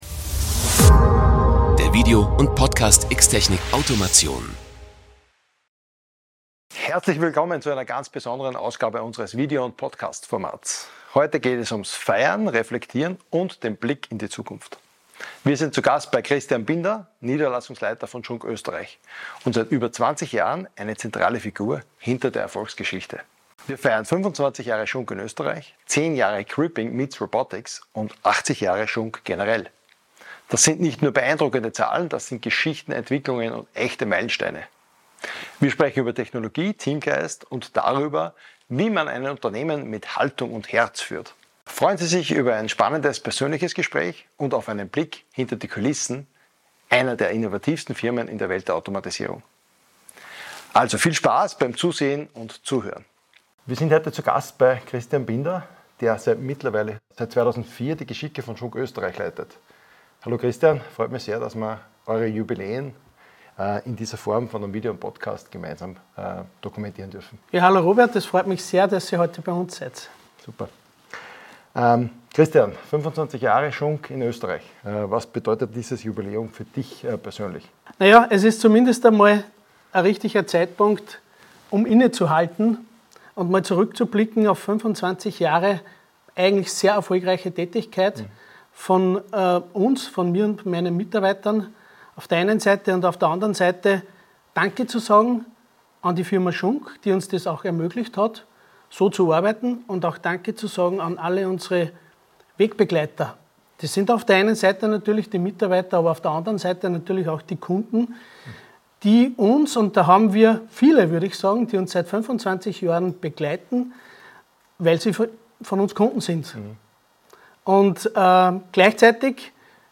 Beschreibung vor 7 Monaten Ein Vierteljahrhundert Schunk Intec Österreich – ein Meilenstein, den man nicht oft feiert.